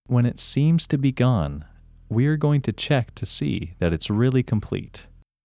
IN – First Way – English Male 21
IN-1-English-Male-21.mp3